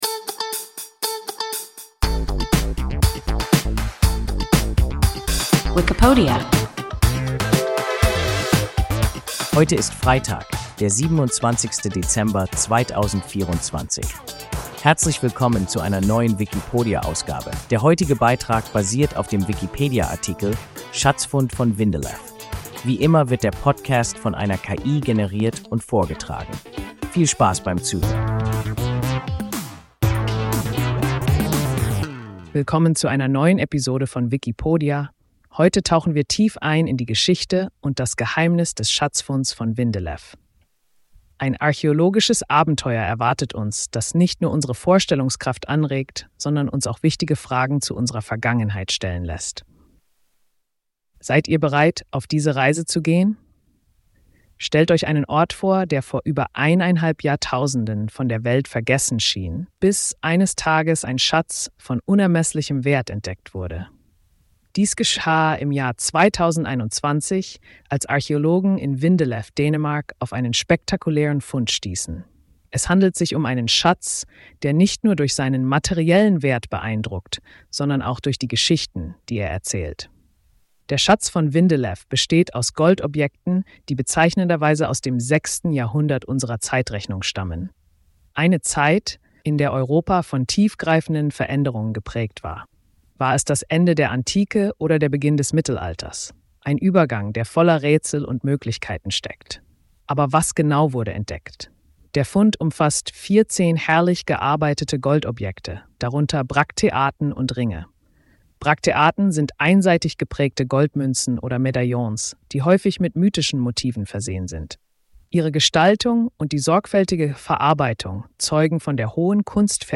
Schatzfund von Vindelev – WIKIPODIA – ein KI Podcast